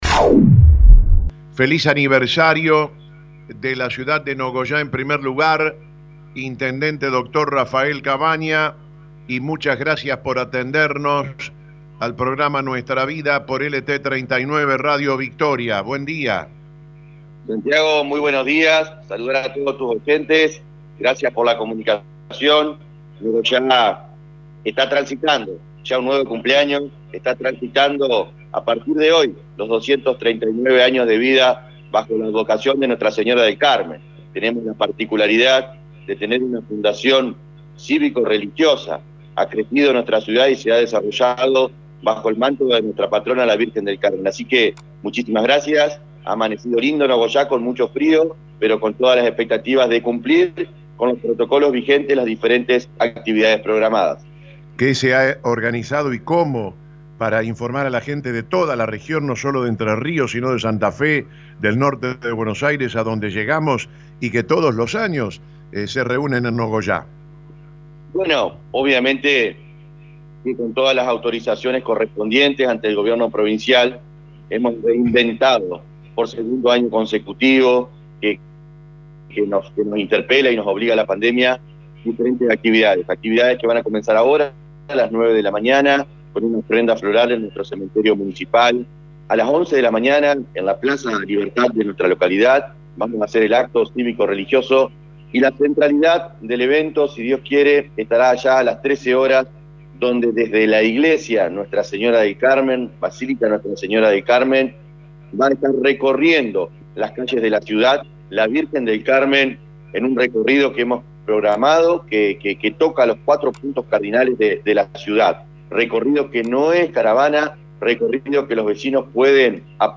“Voy a pedirle a la patrona que no falte la educación y el trabajo.  El trabajo porque es dignidad, la educación, conocimiento…”, manifestó el Intendente quien, en diálogo con Am 980, realizó un agradecimiento especial al personal de salud, e invitó a “cuidarse, para evitar el colapso sanitario”.